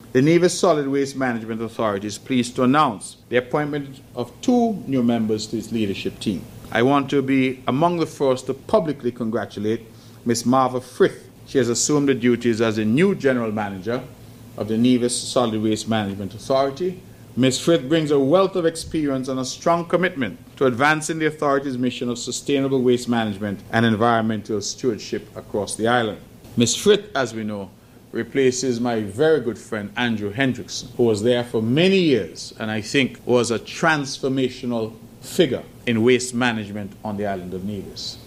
On September 30th 2025, Premier Mark Brantley held his monthly press conference where he addressed several issues affecting Nevis.